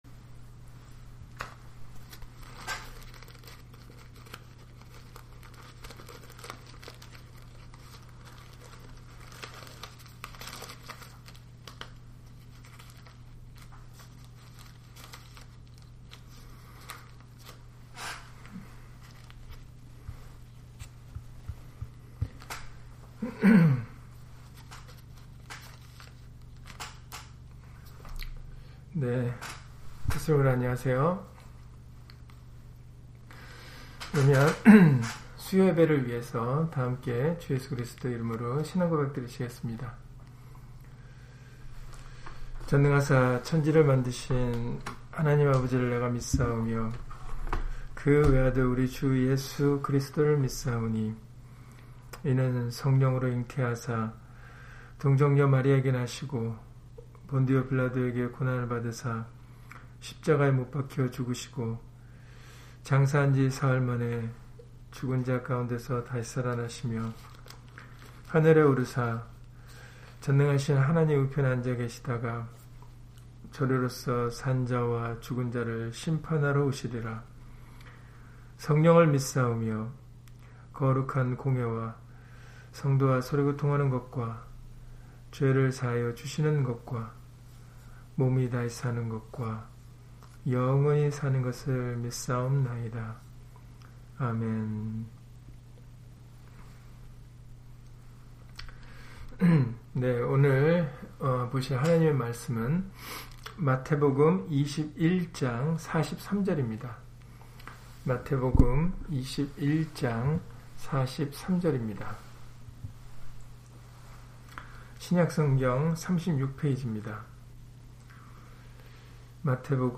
마태복음 21장 43절 [그 나라의 열매 맺는 백성이 받으리라] - 주일/수요예배 설교 - 주 예수 그리스도 이름 예배당